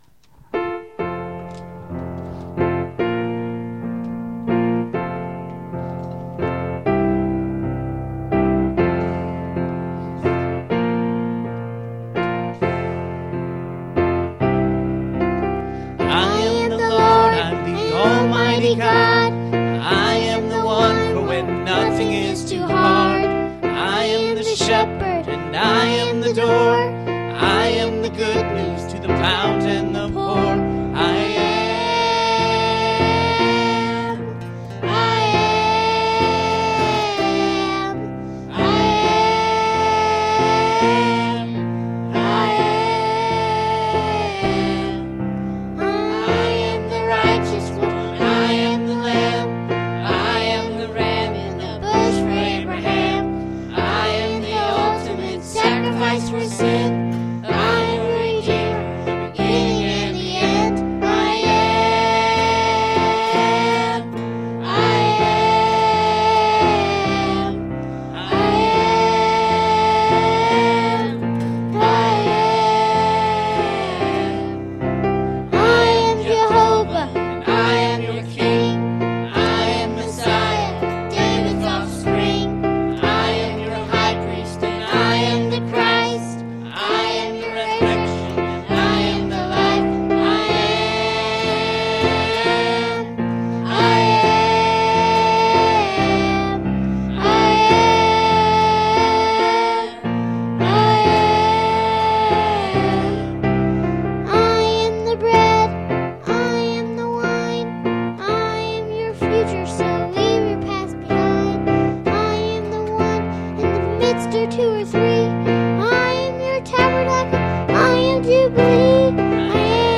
God Knows My Phone Number – Church Of The Open Door
Service Type: Sunday Evening